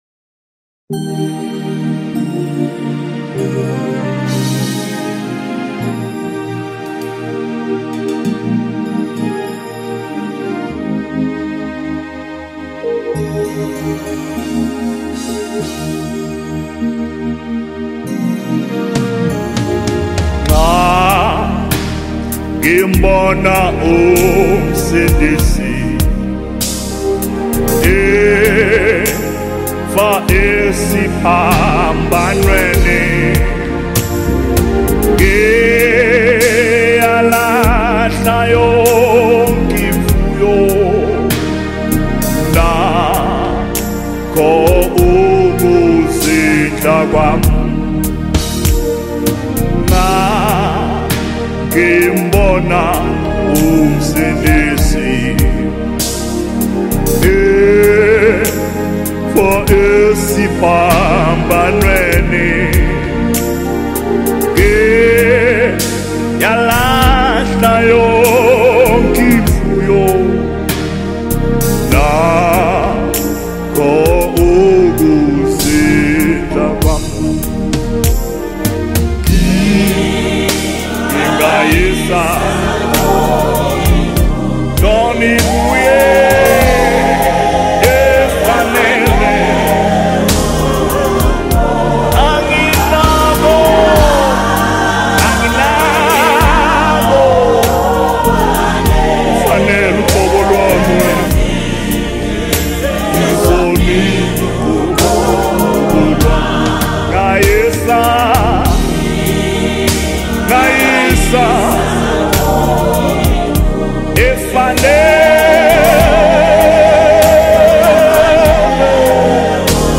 A deep worship song of surrender and devotion
Soul-stirring vocals that inspire deep reflection
📅 Category: South African Worship Song